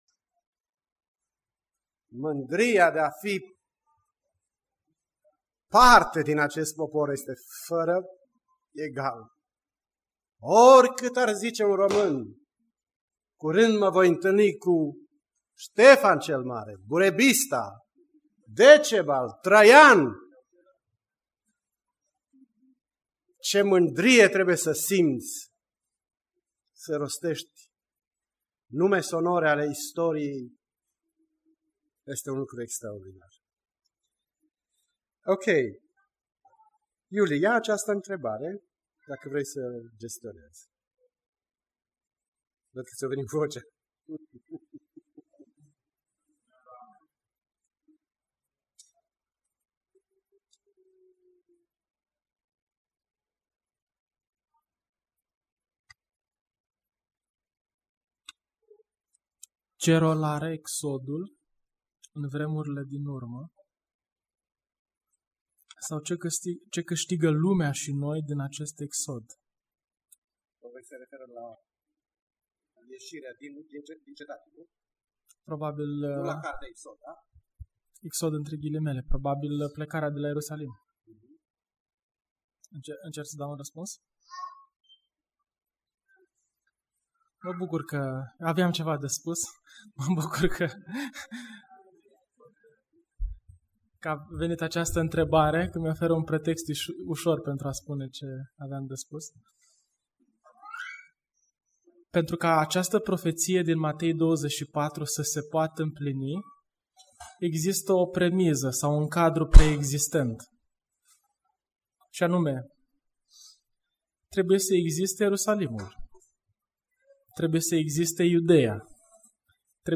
Intrebari si raspunsuri Fii primul care scrie un comentariu acestui material Email unui prieten | Adaugă în lista de favorite Descarca Predica Semnele vremurilor 2.